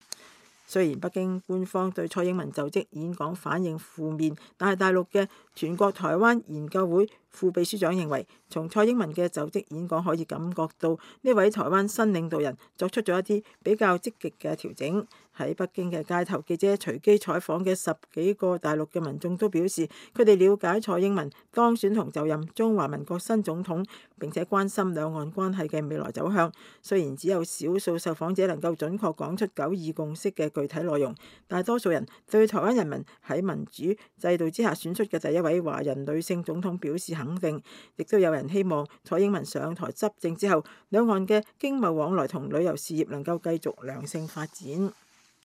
在北京街頭，記者隨機採訪的十幾個大陸民眾都表示，他們了解蔡英文當選和就任中華民國新總統，並且關心兩岸關係的未來走向。儘管只有少數受訪者能準確說出九二共識的具體內容，但是多數人對台灣人民在民主制度下選出第一位華人女性總統表示肯定。